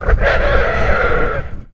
c_horisath_hit3.wav